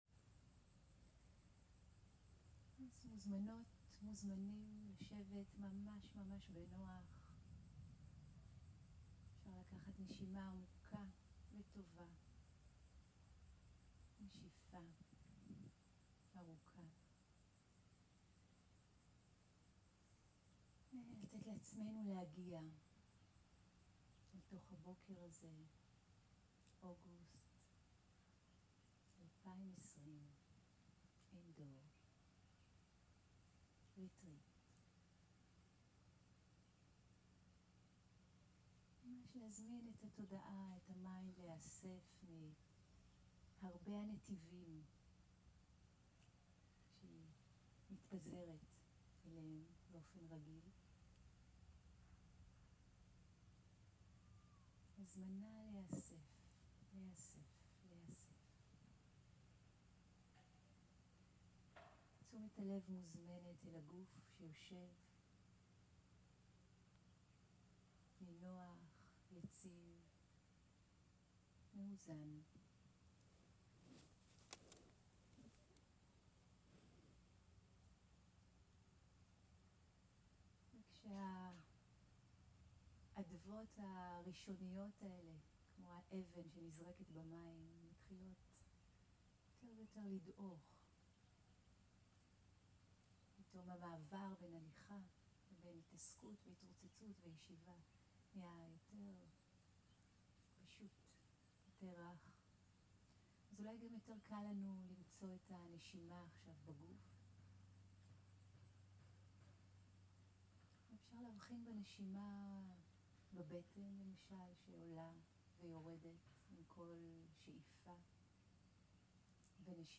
הנחיות בוקר באוויר הפתוח
סוג ההקלטה: שיחת הנחיות למדיטציה
איכות ההקלטה: איכות גבוהה